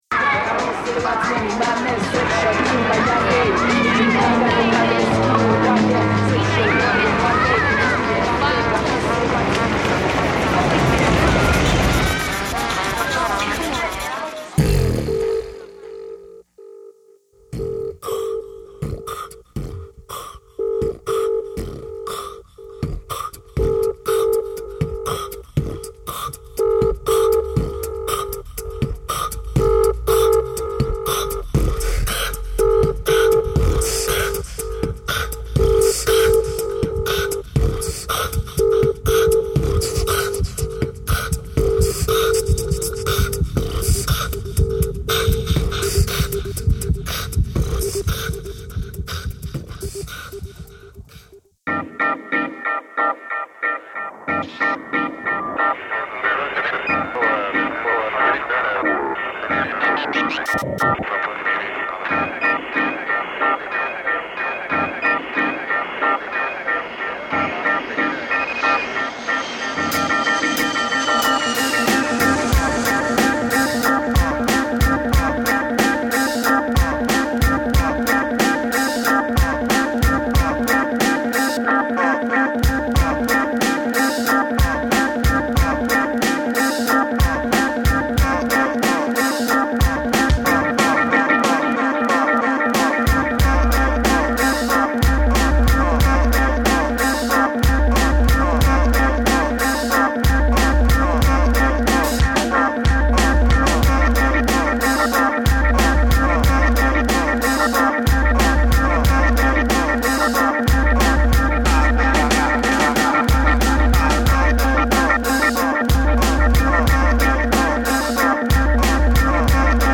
humanBEATBOX